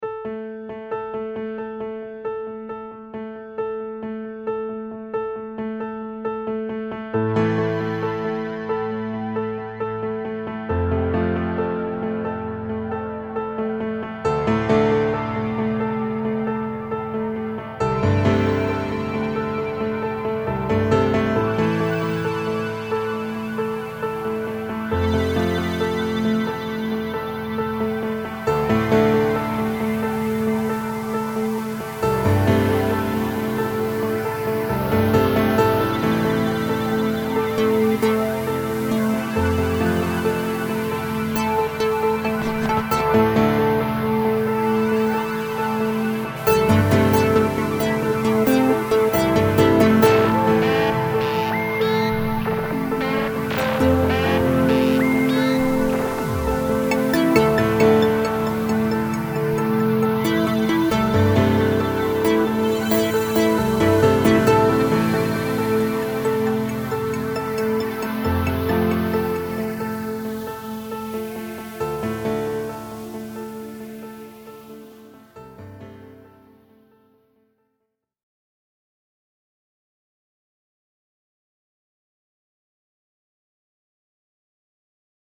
/original compositions/
piano, electronic